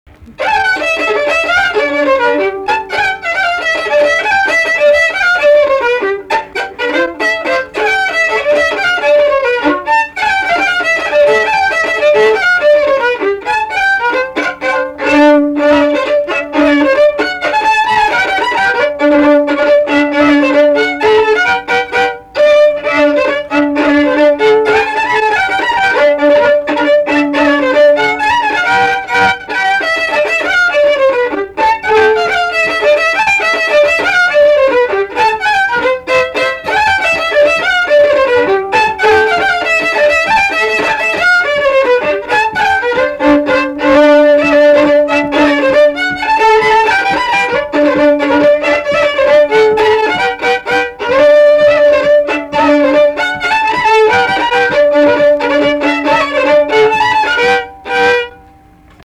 Polka · LMTA Muzikinio folkloro archyvas · omeka
šokis
Luokė
instrumentinis
smuikas